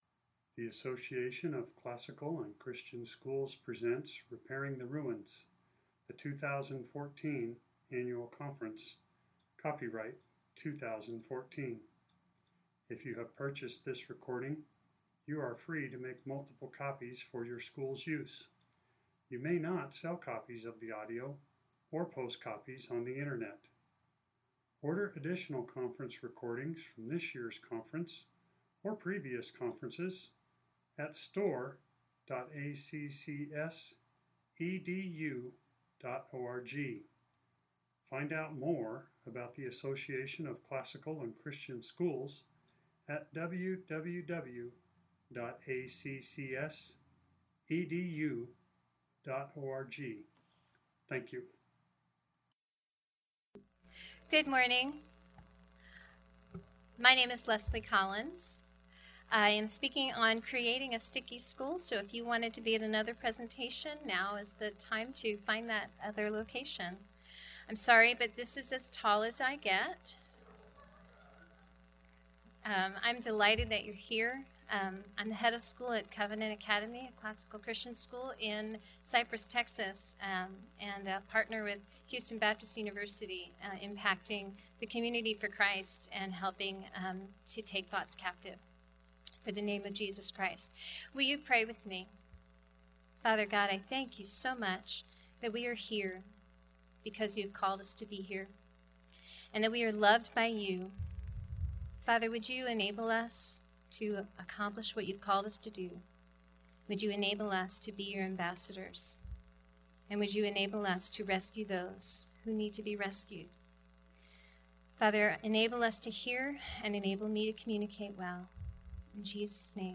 2014 Foundations Talk | 0:51:36 | Leadership & Strategic, Marketing & Growth